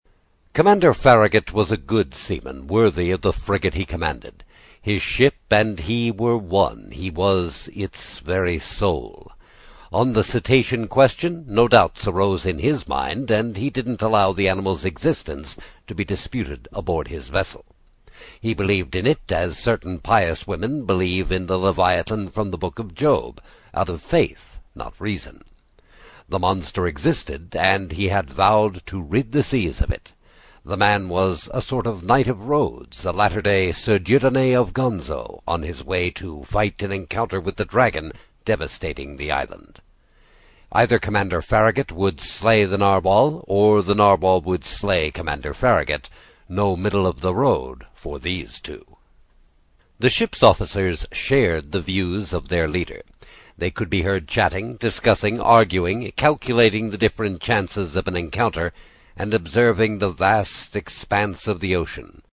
英语听书《海底两万里》第32期 第4章 尼德兰(1) 听力文件下载—在线英语听力室